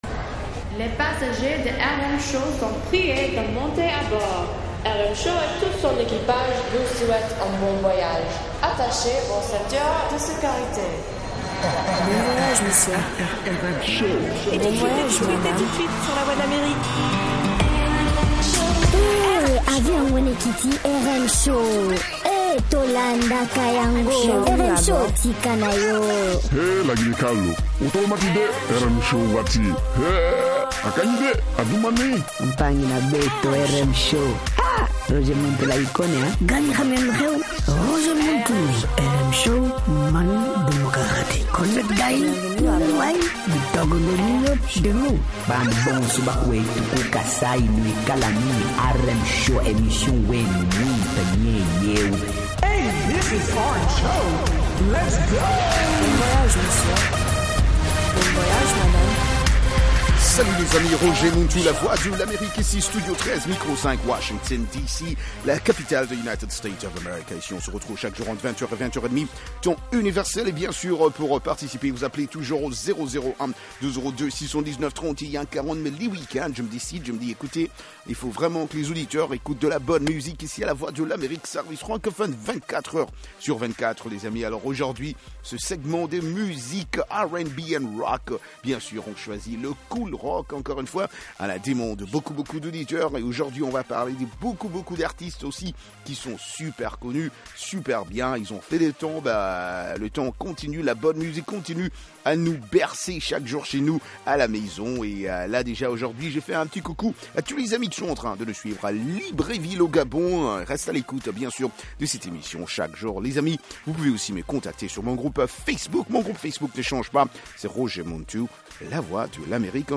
R&B et Rock